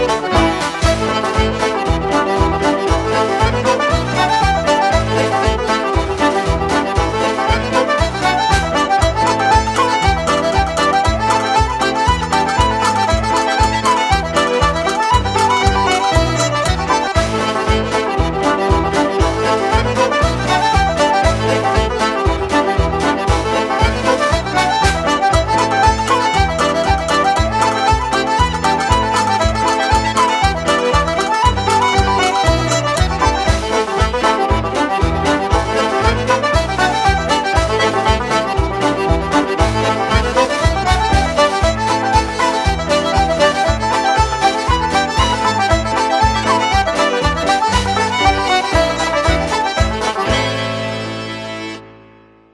Accordion
There’s no shortage of vibrant jigs and reels.